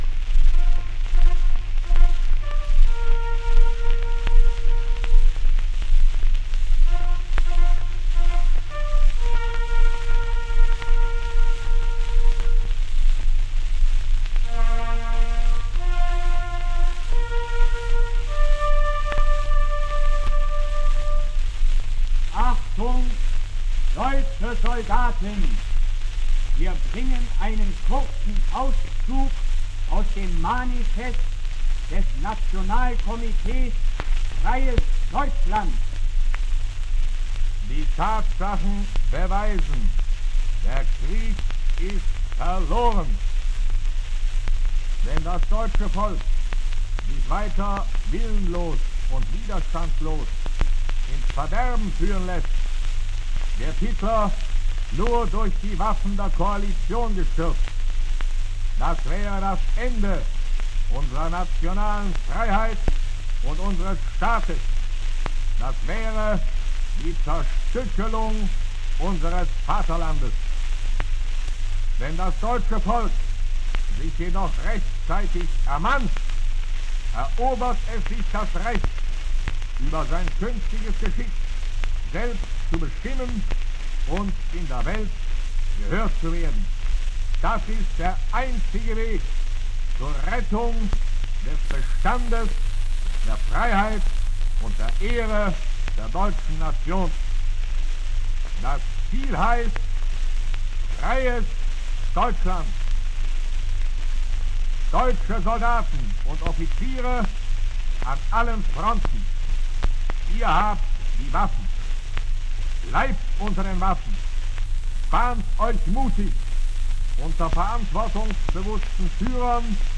Diese Platten sind in der damaligen Sowjetunion vor Ende des zweiten Weltkrieges, um 1943 aufgenommen worden. Hier hat das Nationalkomitee Freies Deutschland Kriegsgefangene Offiziere und Soldaten sprechen lassen. Diese Aufnahmen wurden gesendet, um kämpfende Einheiten von der Sinnlosigkeit des Weiterkämpfens zu überzeugen. Bei diesen Platten handelt es sich um Unikate, es sind die Originalaufnahmen!